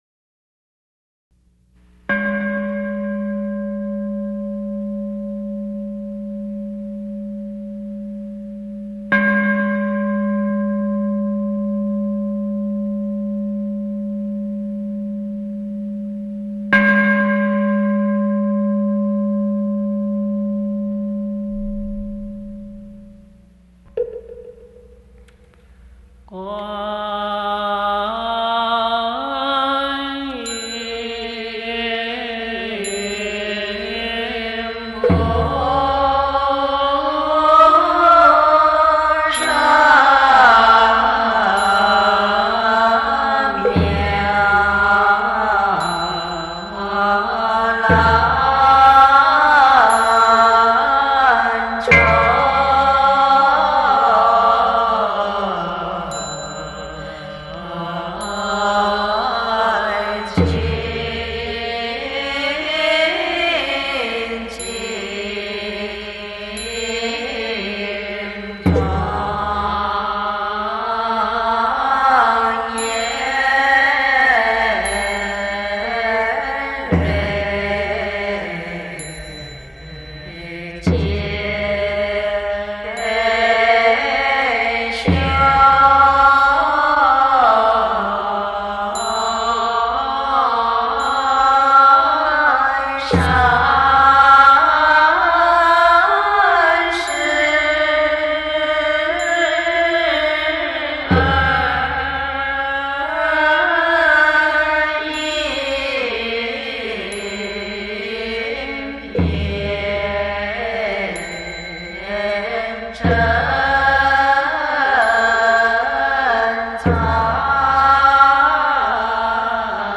类型：中国佛教音乐